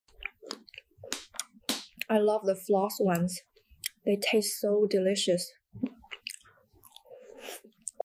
ASMR Kittens Mukbang 😱.